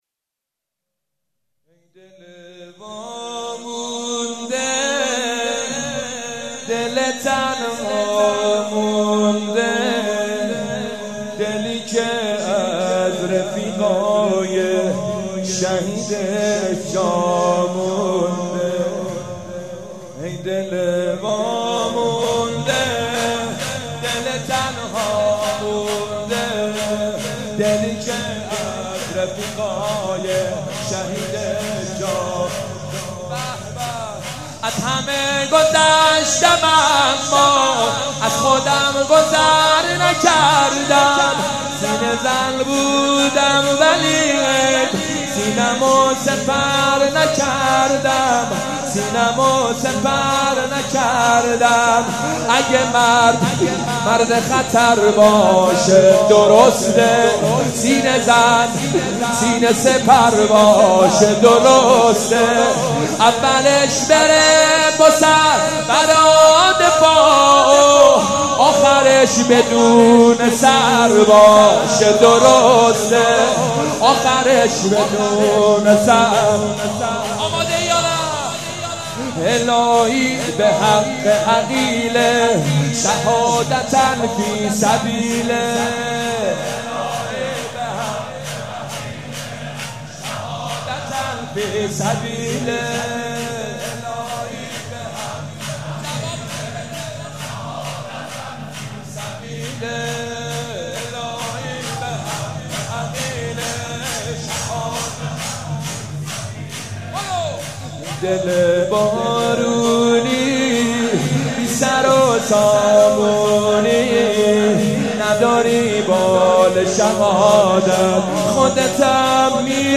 ایام فاطمیه 95
شور - این دل وامونده دل تنها مونده